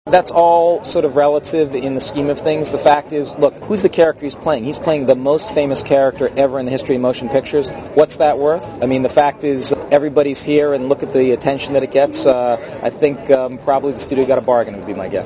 (RadioZet) Źródło: (RadioZet) Mówi Jonathan Mostow Tłumaczenie : Kwestia pieniędzy jest względna.